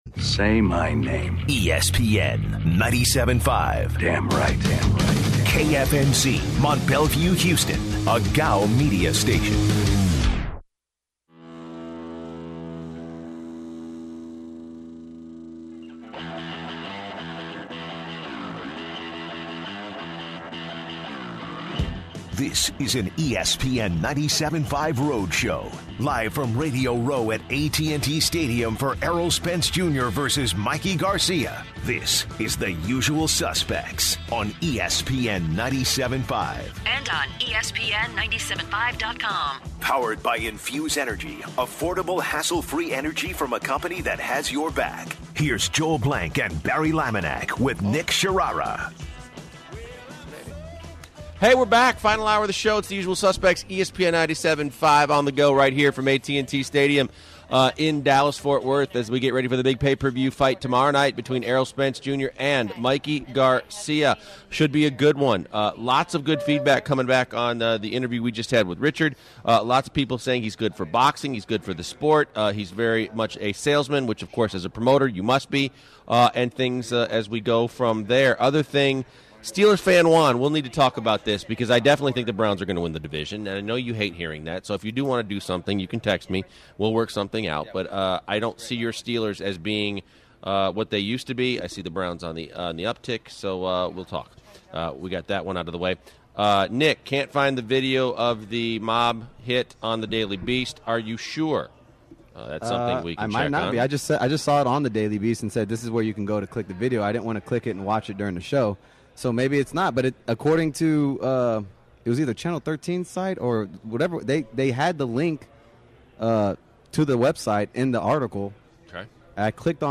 Jimmy Lennon Jr. boxing ring announcer for Showtime and Fox Sports joins the show.
Kate Abdo Turner Sports and Fox Sports boxing reporter joins the show. The guys end the show talking about trash talking going too far and the guys give their picks on the fight.